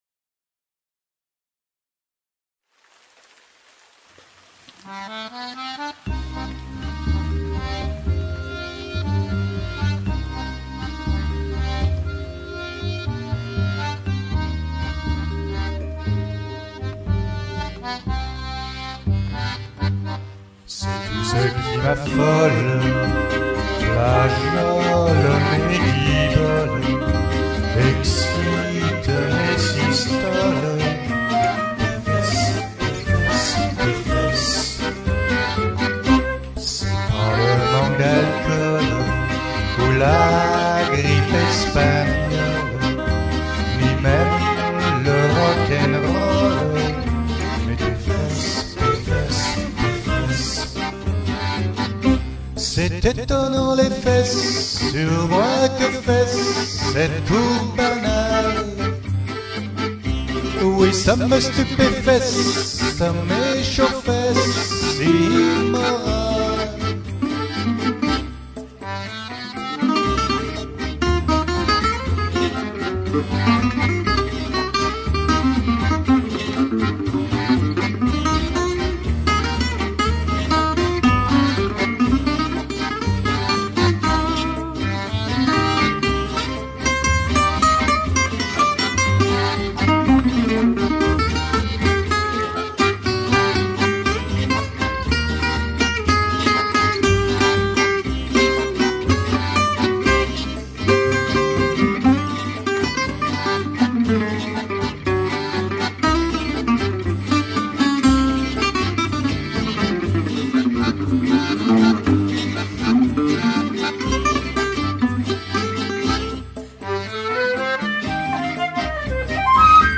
boléro cubain